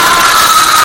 Jump Scare Sound Effect Free Download
Jump Scare